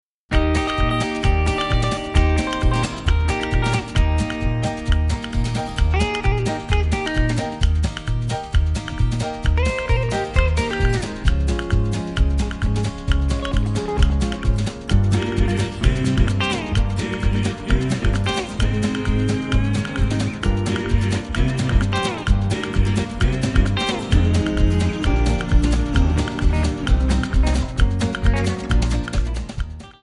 Backing track files: 1960s (842)